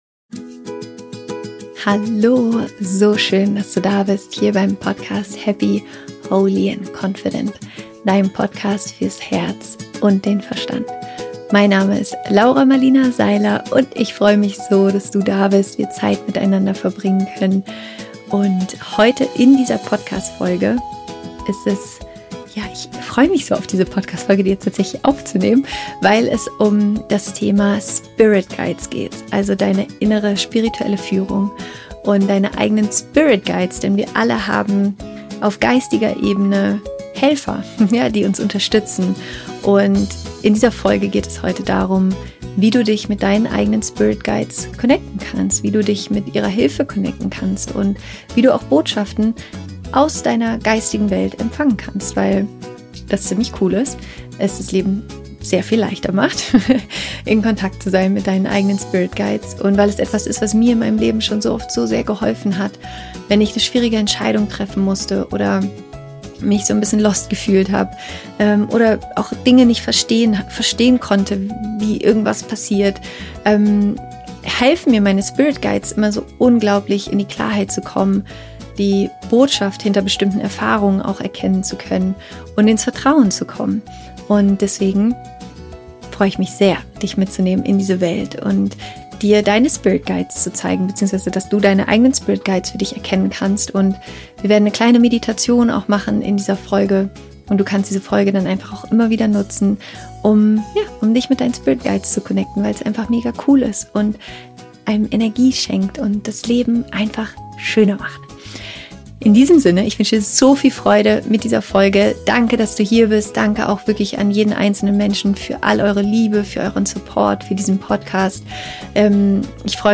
Wir machen gemeinsam eine kleine Meditation, um dich mit deinen Spirit Guides zu connecten und dich mit Energie aufzuladen.